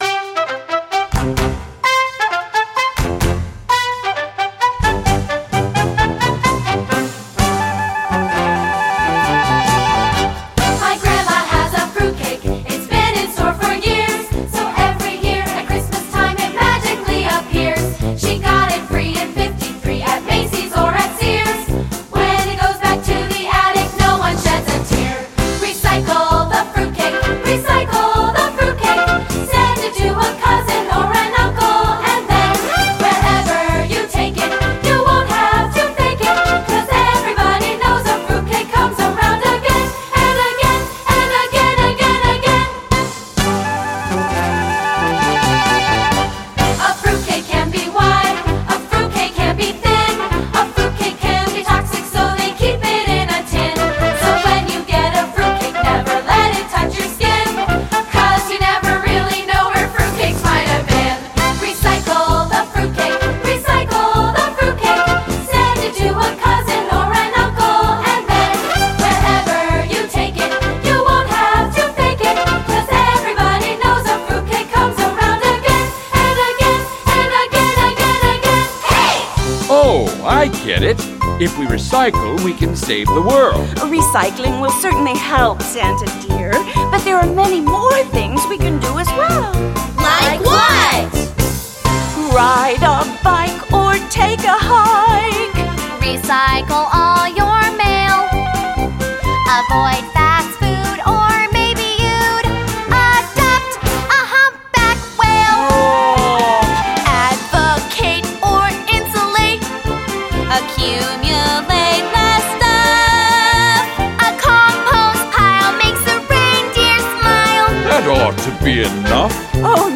Mp3 with singing and dialogue: